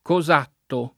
[ ko @# tto ]